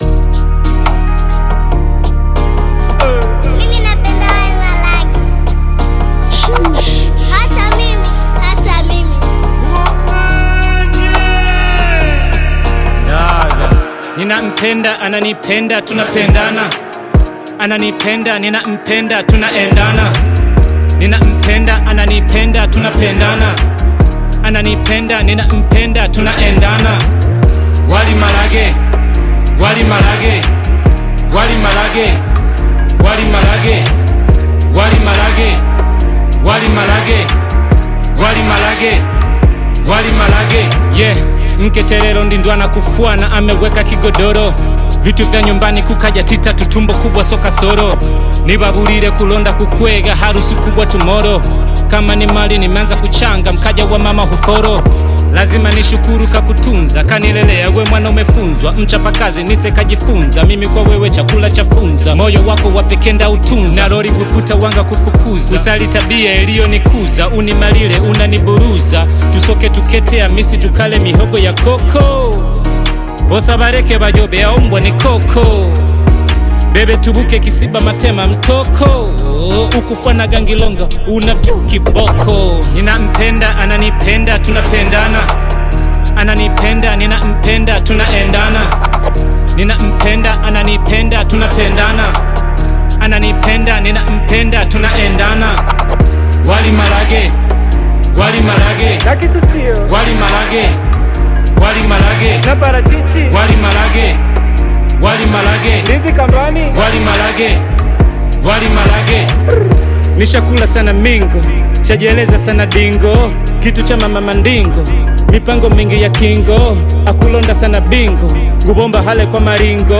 Bongo Flava music track
Tanzanian artist and rapper